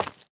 latest / assets / minecraft / sounds / block / tuff / step2.ogg
step2.ogg